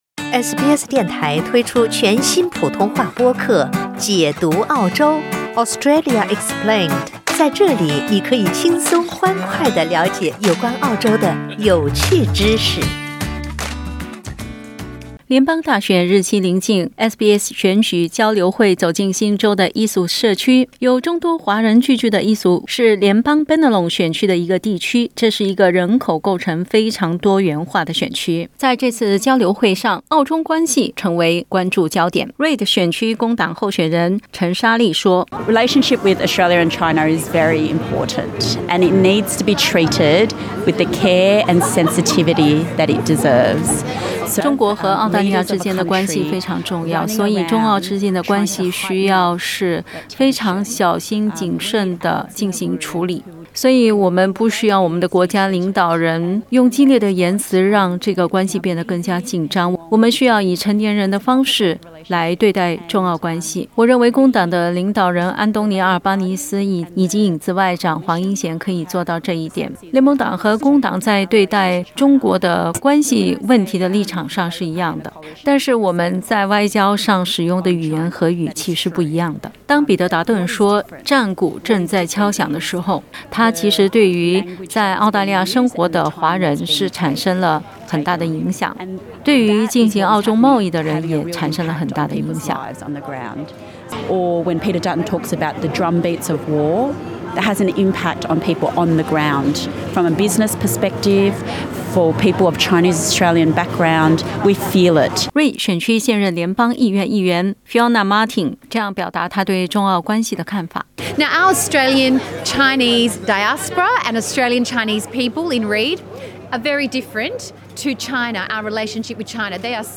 SBS电台于5月7日走进新州Eastwood，举办SBS选举交流会。居住于当地的华人众多，澳中关系成为社区关注的焦点，多个联邦选区的议员、候选人和选民是如何看待的呢？